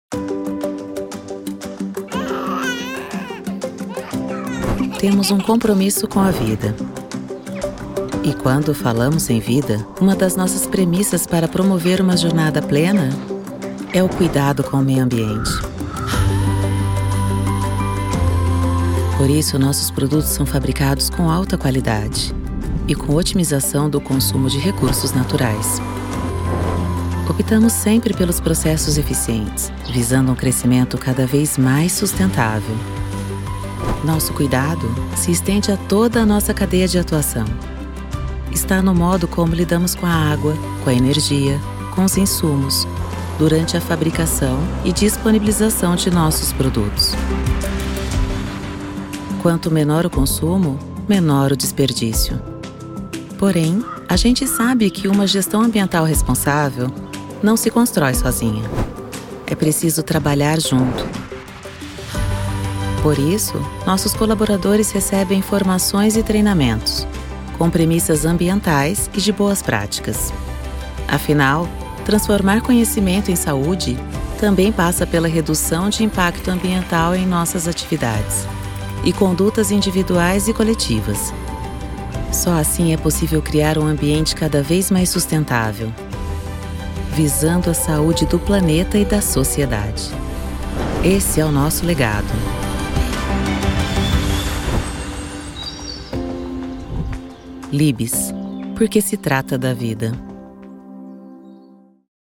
Vídeos Corporativos
Trabalho em estúdio próprio totalmente equipado e entrego áudio de alta qualidade, com flexibilidade para atender necessidades exclusivas da sua marca, negócio ou projeto. Minha voz é versátil, polida e neutra, com interpretações personalizadas para seus objetivos.
Contralto